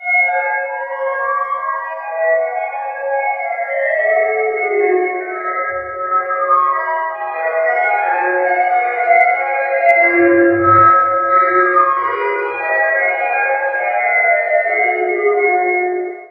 icecreamtruck
chime cream ice icecream ice-cream-van melodic music neighborhood sound effect free sound royalty free Music